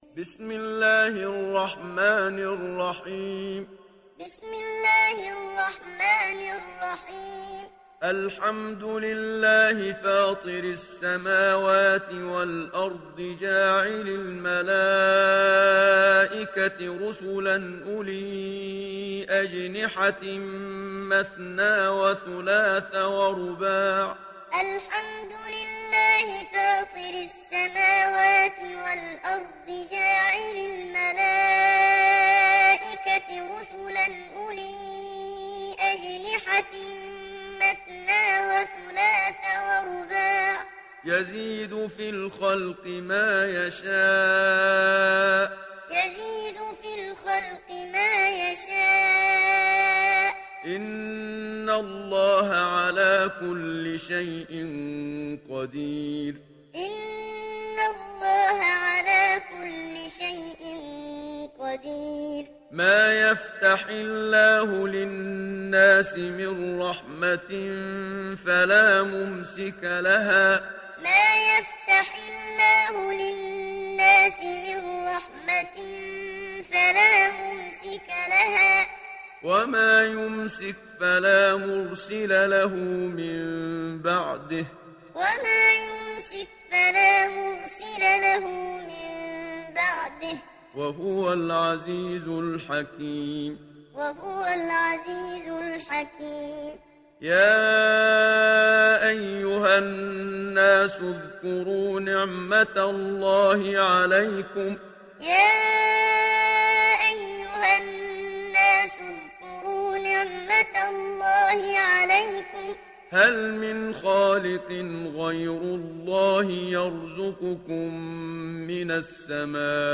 دانلود سوره فاطر mp3 محمد صديق المنشاوي معلم روایت حفص از عاصم, قرآن را دانلود کنید و گوش کن mp3 ، لینک مستقیم کامل
دانلود سوره فاطر محمد صديق المنشاوي معلم